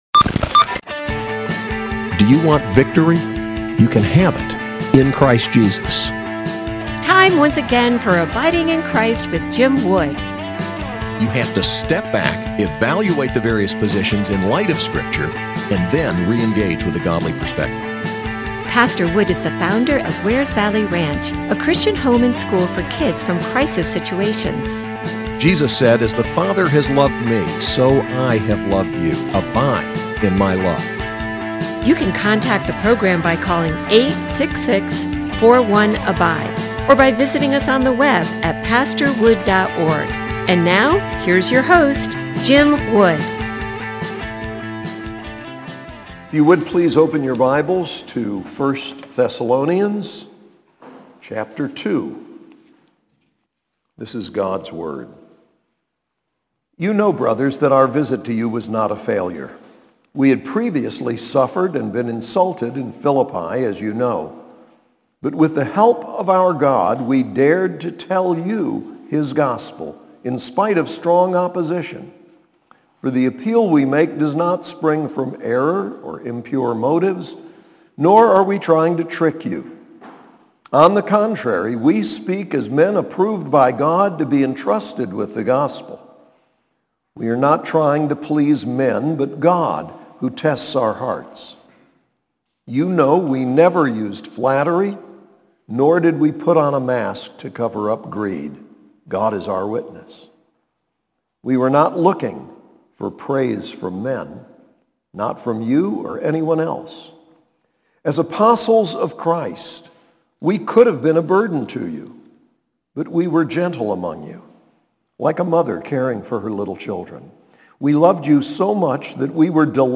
SAS Chapel: 1 Thessalonians 2